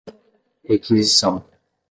Pronunciado como (IPA)
/ʁe.ki.ziˈsɐ̃w̃/